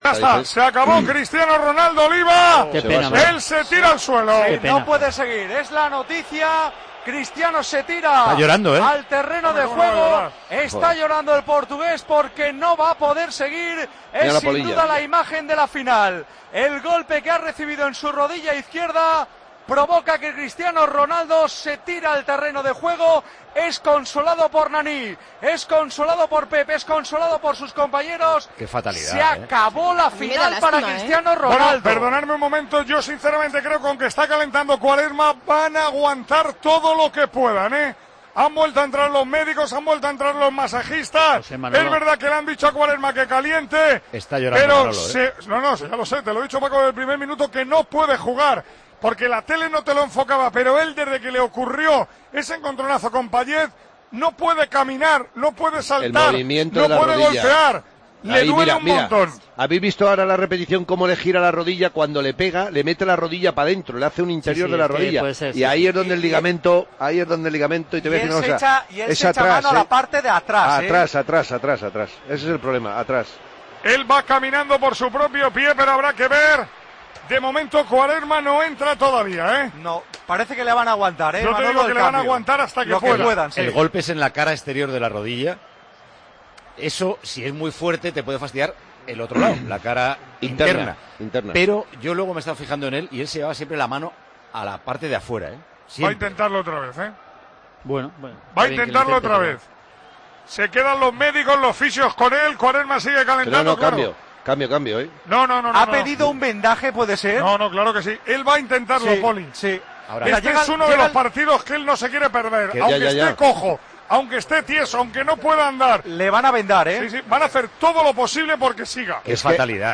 El momento de la lesión de Cristiano Ronaldo, narrado por Manolo Lama